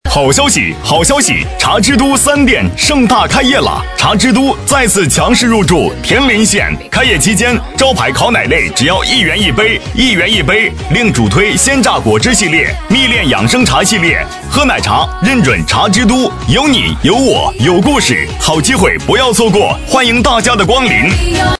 223男声样音试听
223男声茶之都.mp3